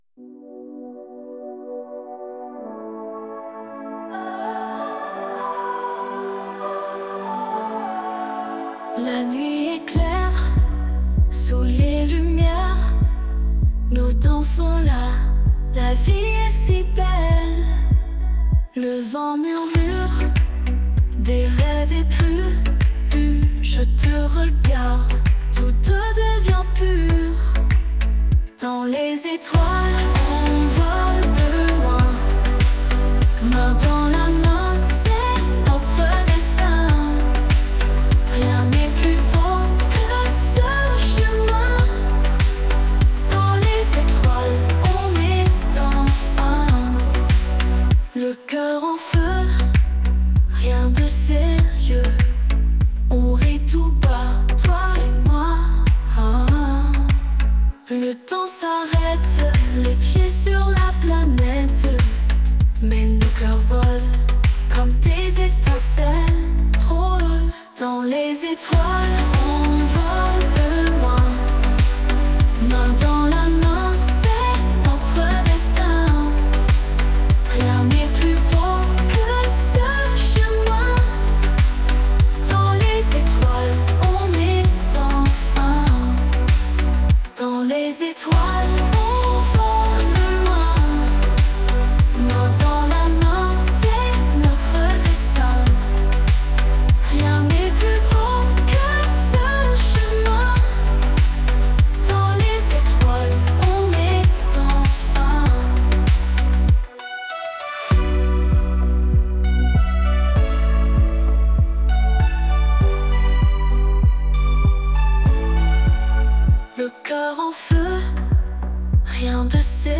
Voici ce que l’on peut obtenir en écrivant « Une chanson de pop française » comme prompt.